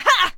combat / creatures / default / she / attack1.ogg
attack1.ogg